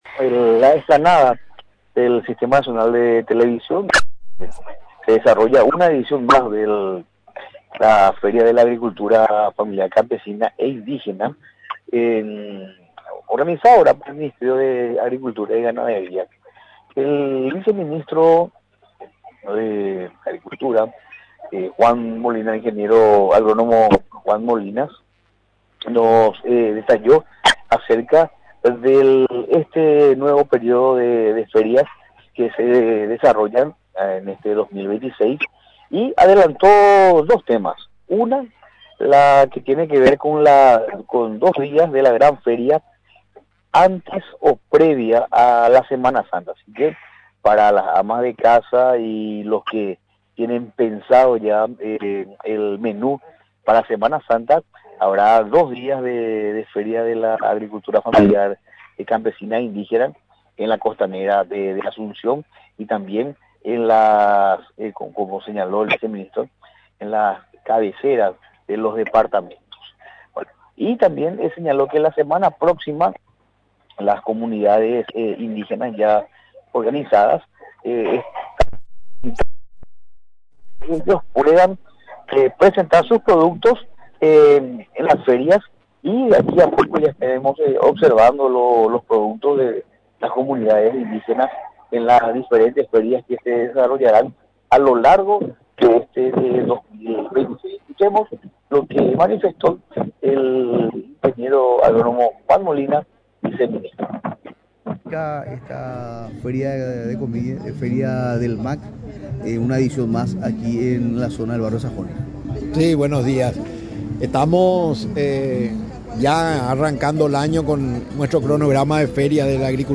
Previo a la Semana Santa, el Ministerio de Agricultura y Ganadería, prepara dos días de Feria de la Agricultura Familiar en la Costanera de Asunción, adelantó este jueves el viceministro del MAG, ingeniero agrónomo Juan Molinas.